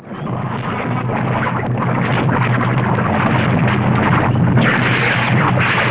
Free Sound Effects
Lavaflow.mp3